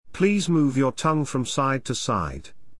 ﾌﾟﾘｰｽﾞ ﾑｰﾌﾞ ﾕｱ ﾀﾝｸﾞ ﾌﾛﾑ ｻｲﾄﾞ ﾄｩ ｻｲﾄﾞ